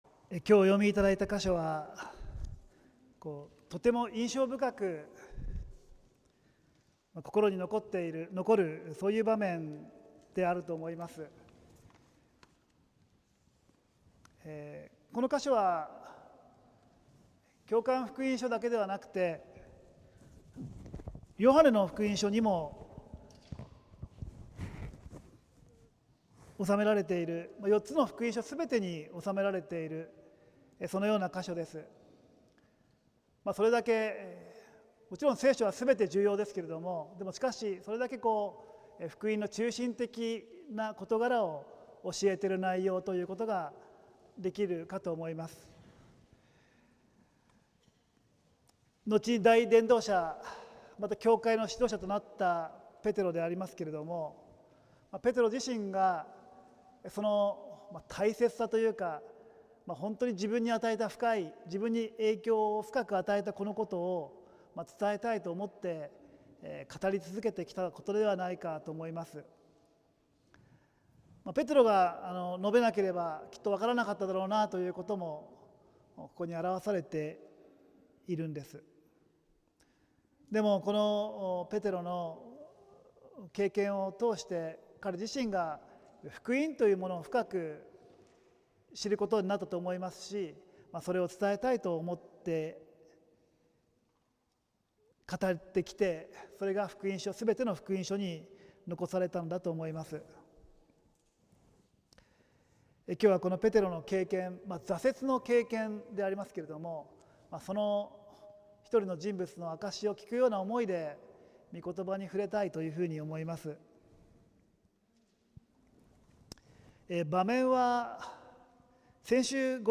浦和福音自由教会(さいたま市浦和区)の聖日礼拝(2025年5月4日)「主イエスを知らないと言う」(週報とライブ/動画/音声配信)